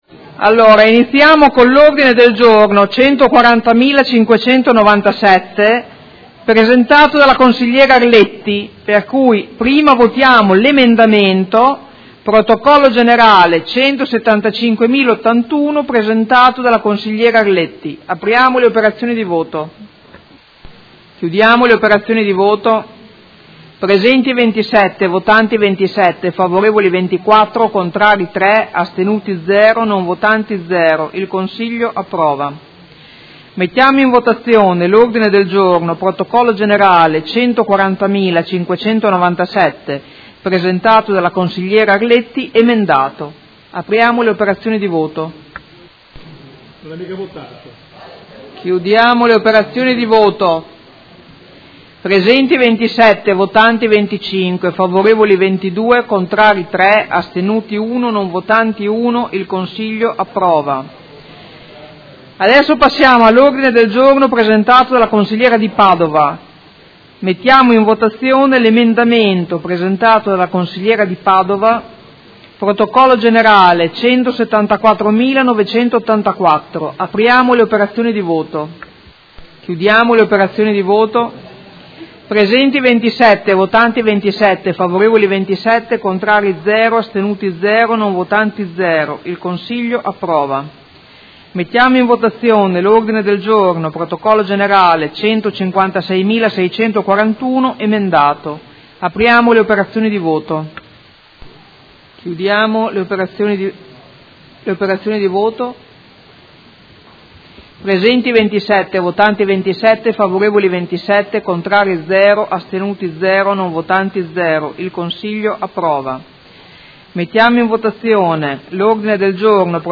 Presidente — Sito Audio Consiglio Comunale
Seduta dell’8/11/2018.